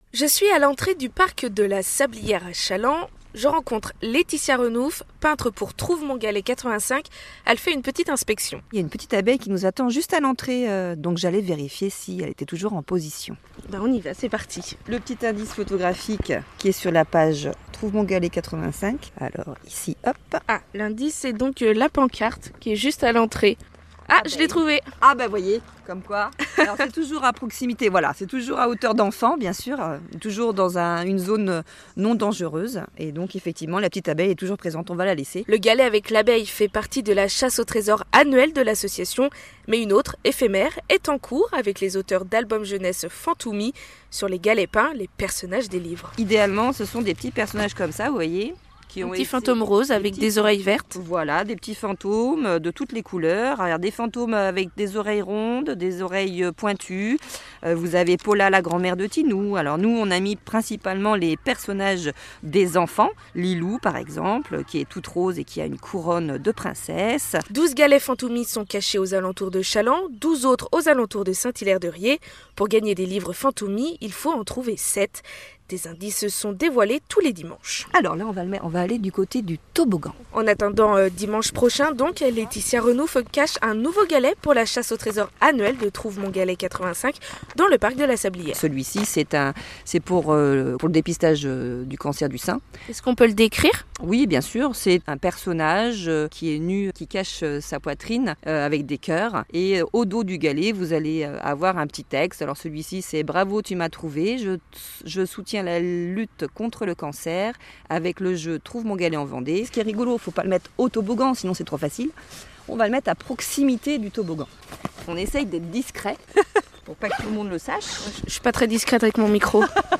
Podcast reportages